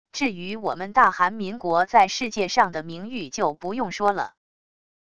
至于我们大韩民国在世界上的名誉就不用说了wav音频生成系统WAV Audio Player